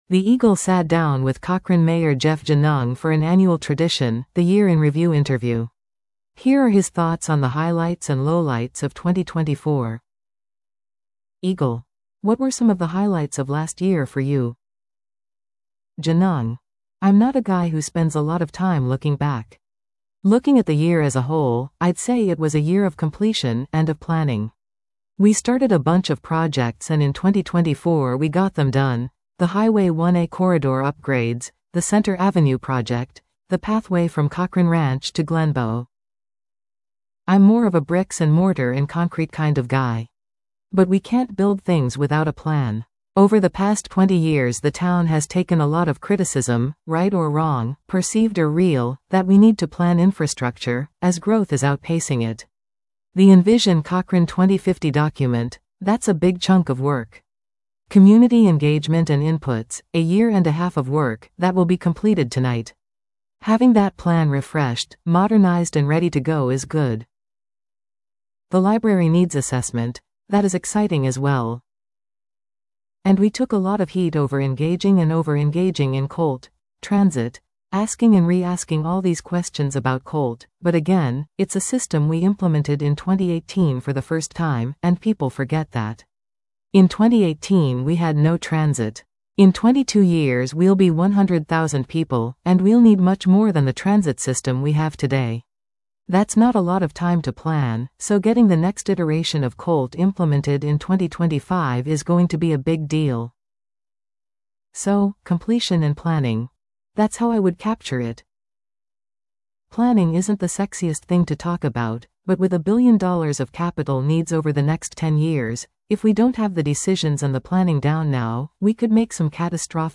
The Eagle sat down with Cochrane Mayor Jeff Genung for an annual tradition – the Year in Review interview. Here are his thoughts on the highlights and lowlights of 2024.